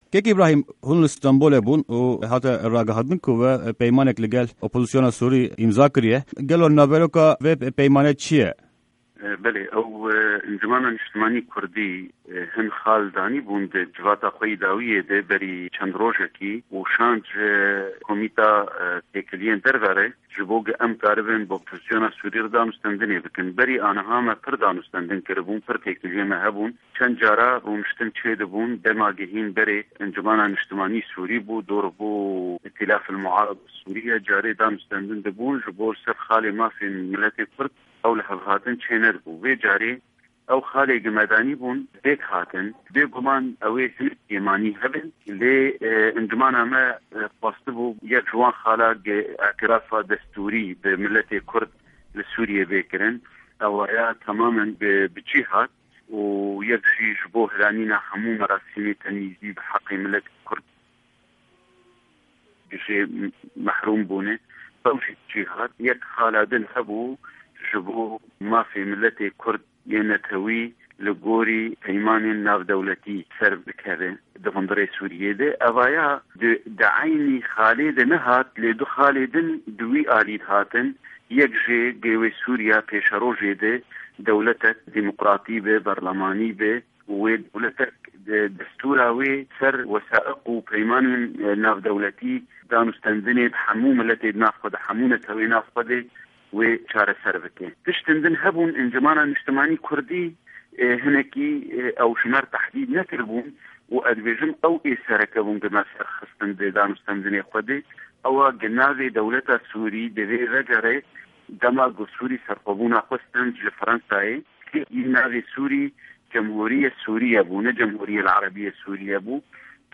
Di hevpeyvîna Dengê Amerîka